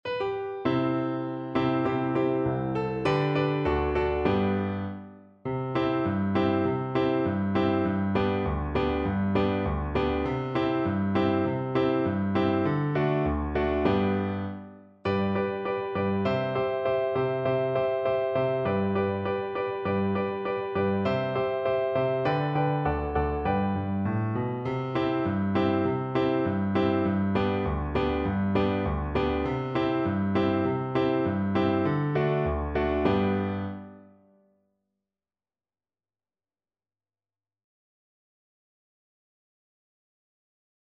Cello version
2/4 (View more 2/4 Music)
Brightly =c.100